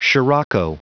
Prononciation du mot sirocco en anglais (fichier audio)
Prononciation du mot : sirocco